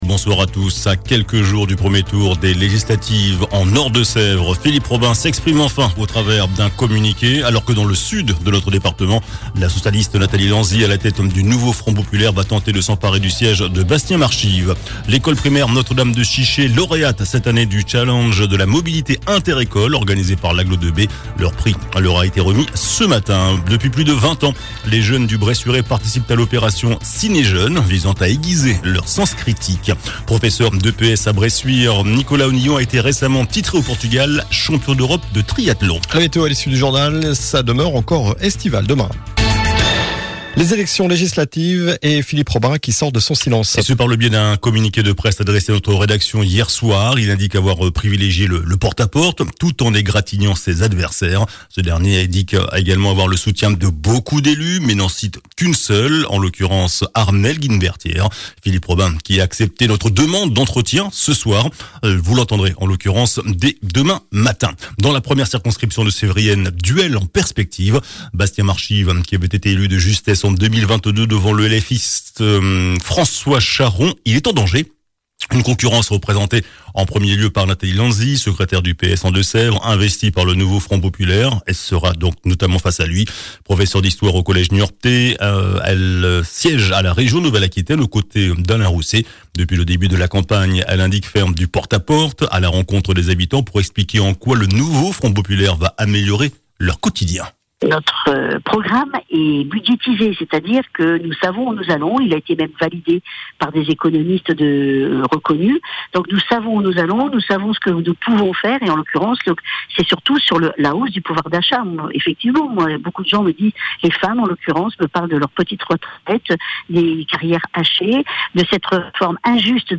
JOURNAL DU JEUDI 27 JUIN ( SOIR )